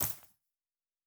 Coin and Purse 04.wav